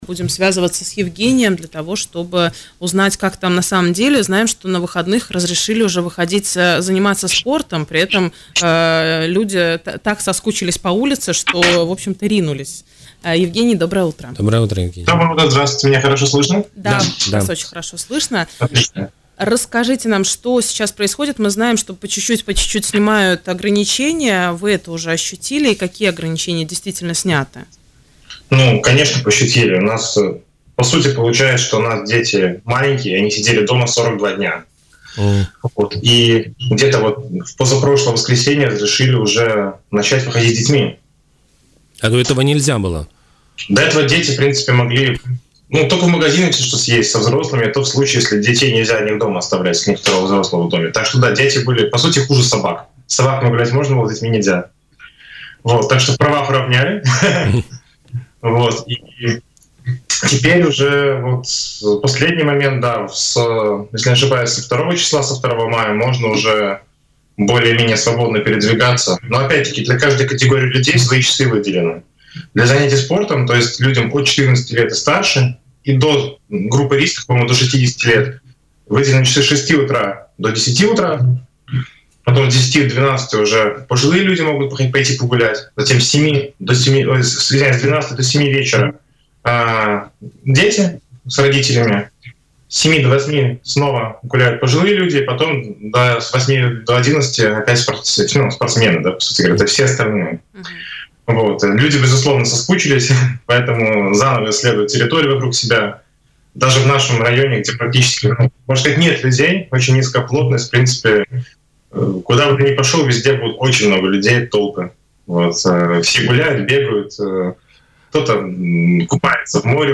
Испания постепенно снимает карантин. О том, как именно различные отрасли возобновляют свою работу, в интервью радио Baltkom рассказал житель Каталонии